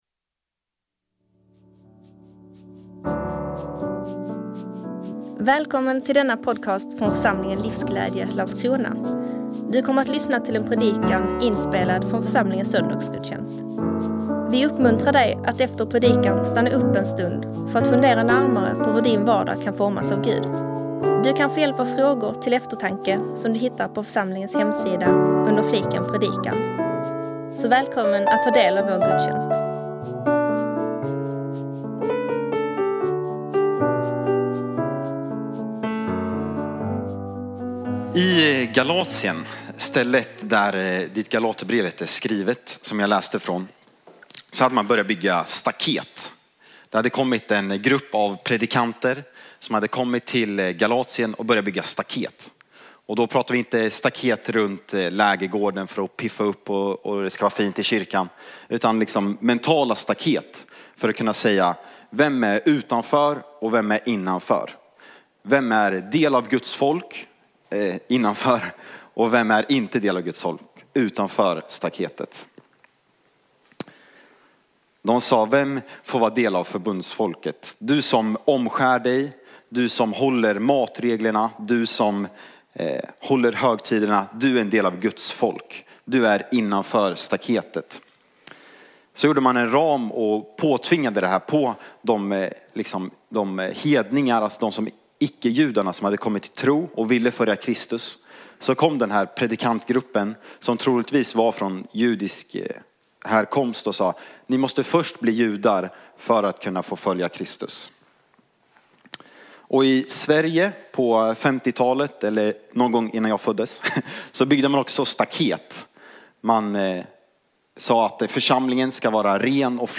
24 november 2024 Andens frukt Predikant